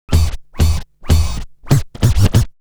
Scratch 14.wav